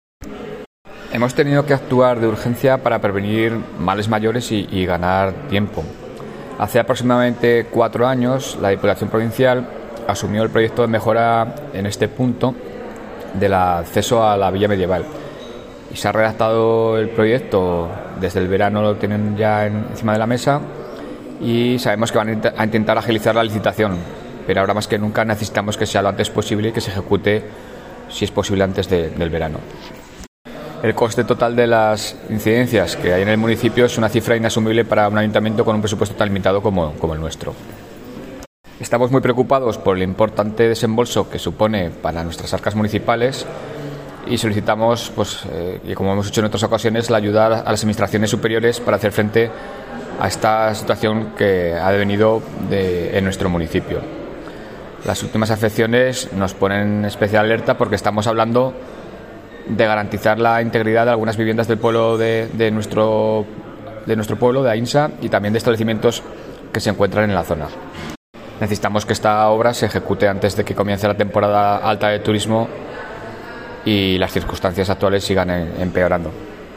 Declaraciones-Enrique-Pueyo-afecciones-por-lluvias-en-Ainsa.mp3